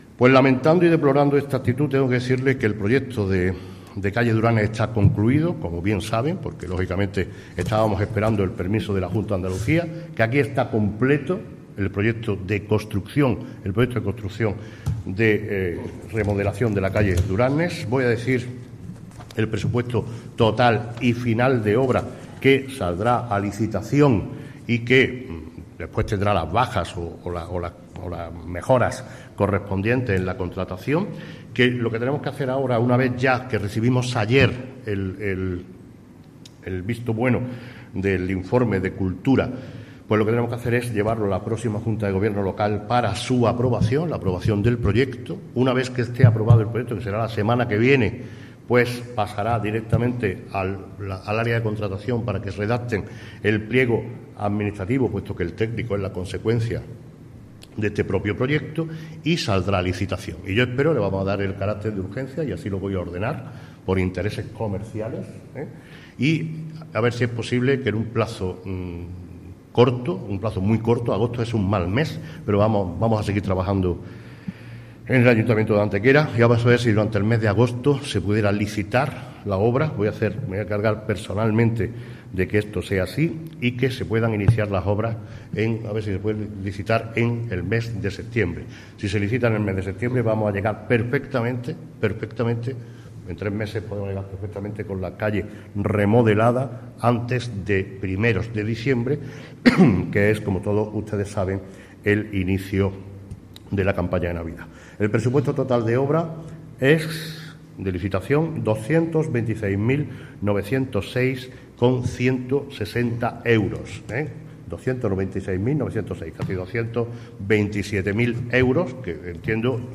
El alcalde de Antequera, Manolo Barón, el teniente de alcalde delegado de Obras y Mantenimiento, José Ramón Carmona, y la teniente de alcalde de Turismo, Comercio y Promoción para el Empleo, Belén Jiménez, han confirmado hoy en rueda de prensa que el proyecto técnico para la remodelación integral de la calle Duranes –considerada como el centro neurálgico del Centro Comercial Abierto– se aprobará en la próxima sesión de la Junta de Gobierno Local, a celebrar la próxima semana.
Cortes de voz